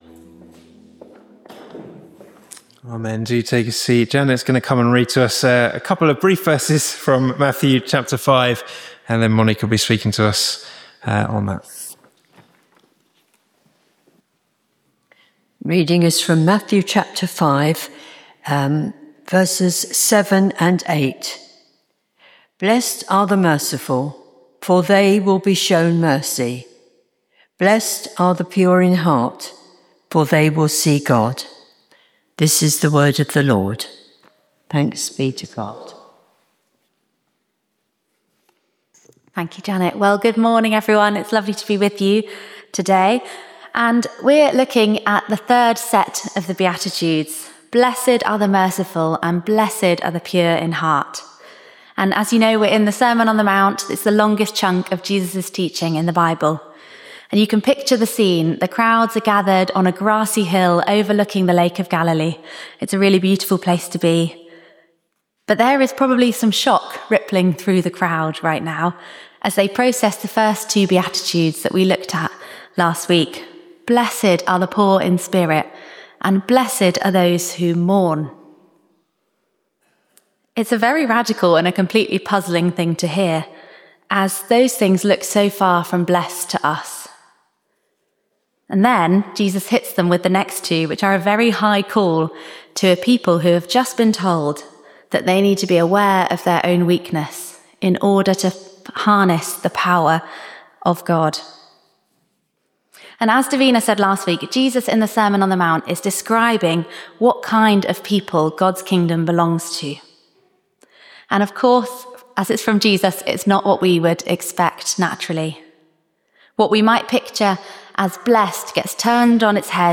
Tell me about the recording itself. Location: St Mary’s, Slaugham Date Service Type: Informal Praise